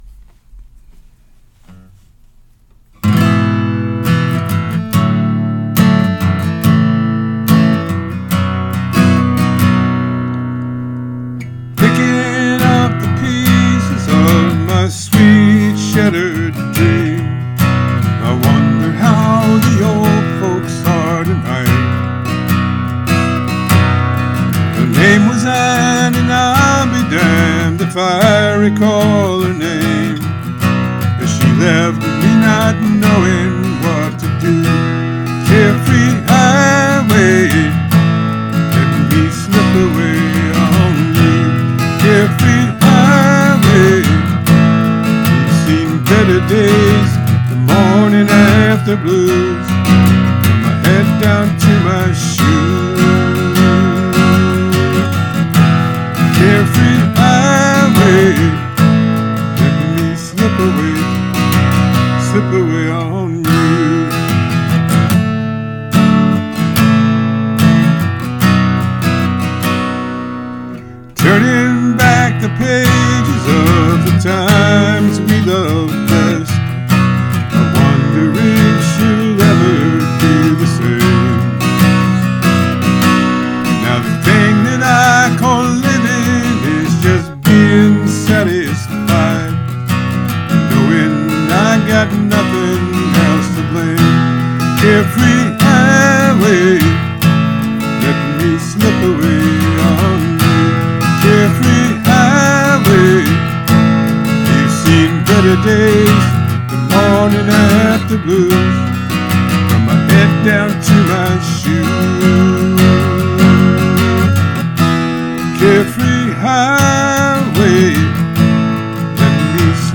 Here's my cover.